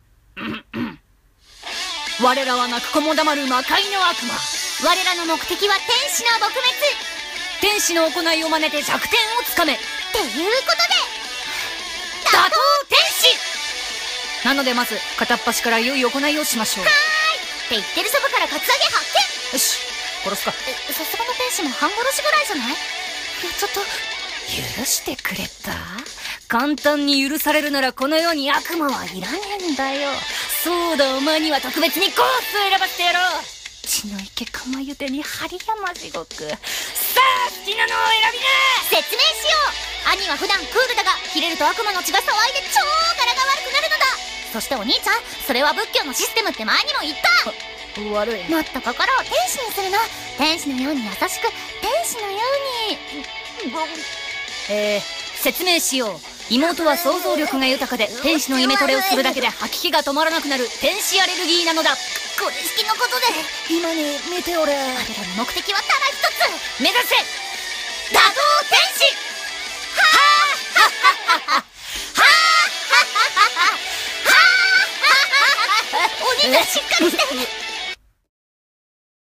【声劇台本】打倒天使！【掛け合い】